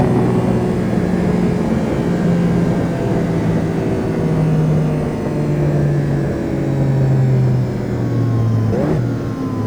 Index of /server/sound/vehicles/vcars/lamboaventadorsv
throttle_off_highspeed.wav